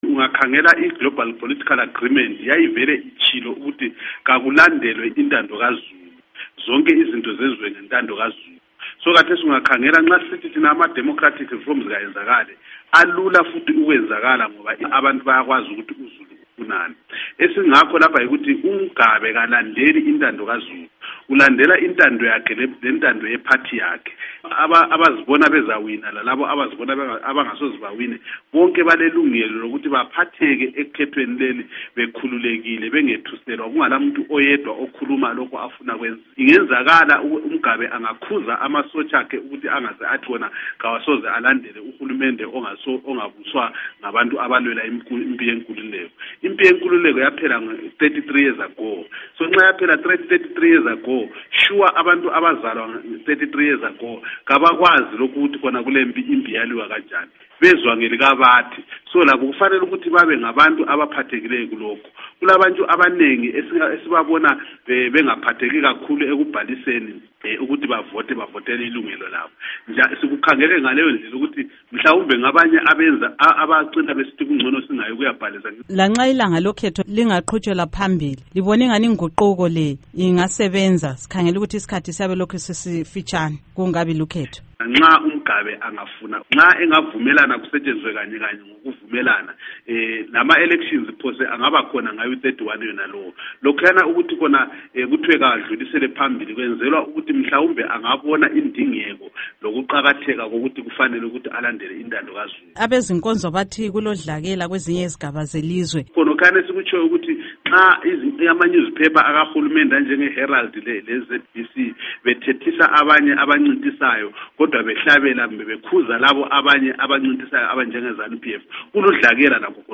Ingxoxo LoMnu. Abednico Bhebhe